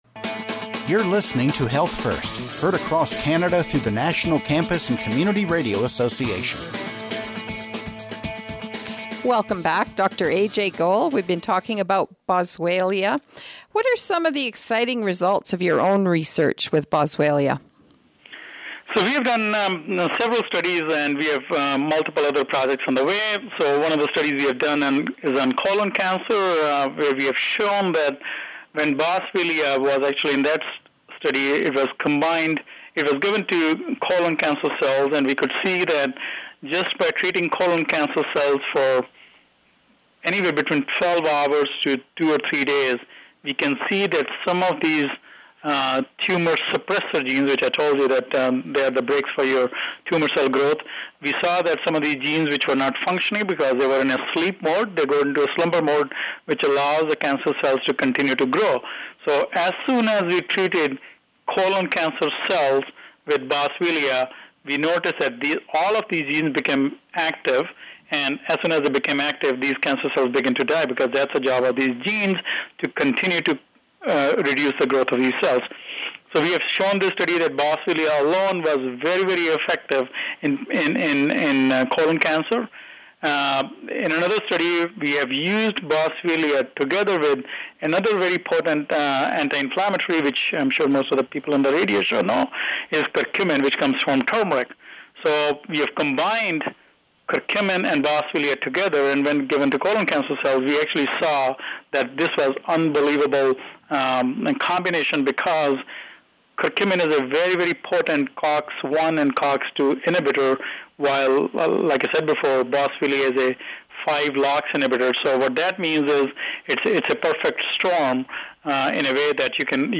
Recording Location: 93.1 CFIS-FM, Prince George, BC
Type: Interview
160kbps Stereo